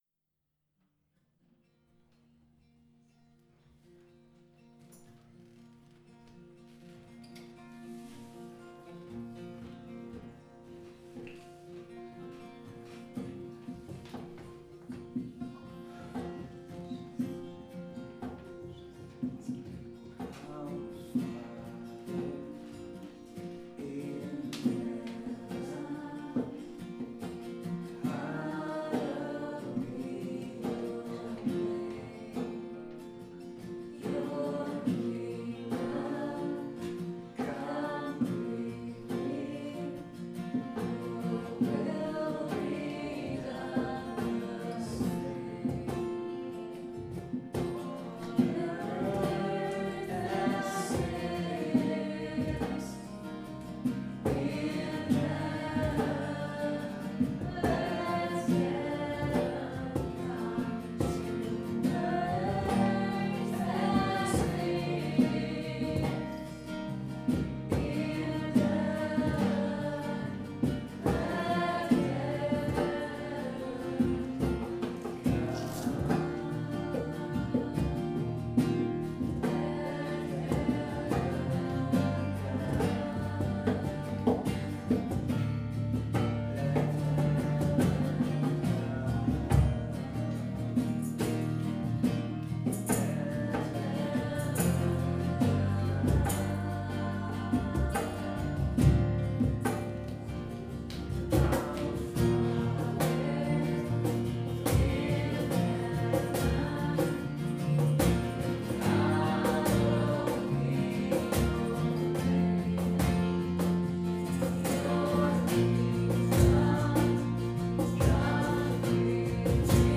Ein kleiner, wundervoller Auszug für euch aus der Anbetungszeit vom diesjährigen Freundeurlaub in Untertiefengrün.
Freundeurlaub-2014_Our-Father.mp3